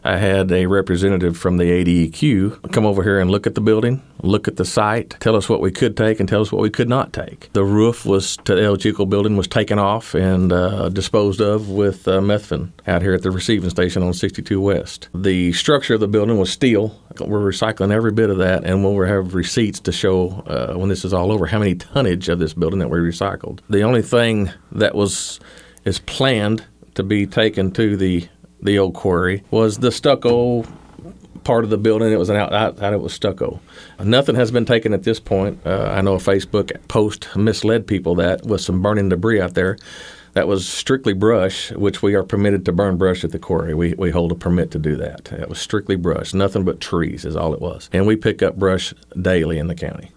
Judge Litty spoke on the involvement of environmental officials as the El Chico building is taken down as well as burning at the quarry.